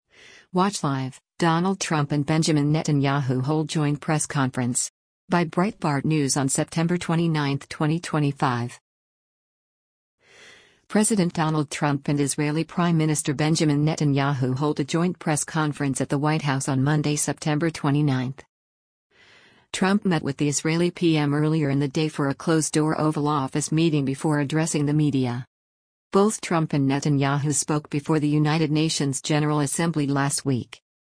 President Donald Trump and Israeli Prime Minister Benjamin Netanyahu hold a joint press conference at the White House on Monday, September 29.